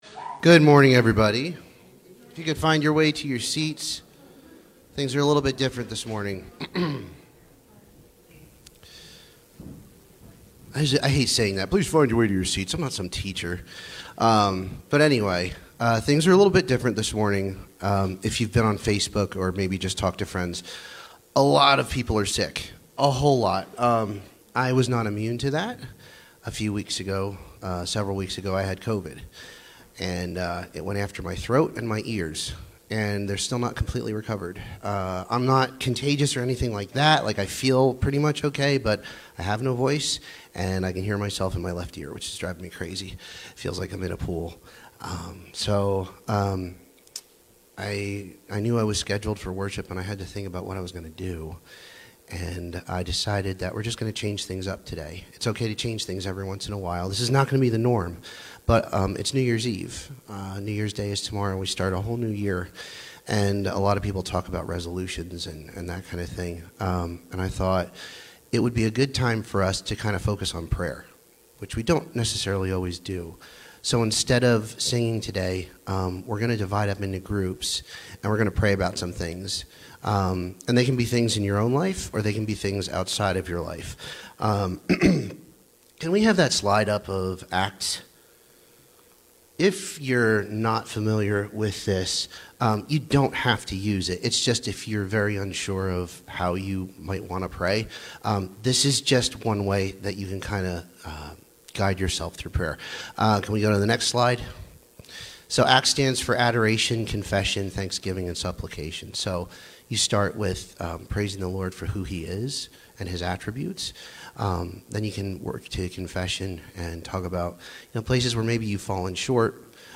Series: CCC Sermons
John 3:16 Service Type: Sunday Morning What is God’s purpose for us at Cornerstone?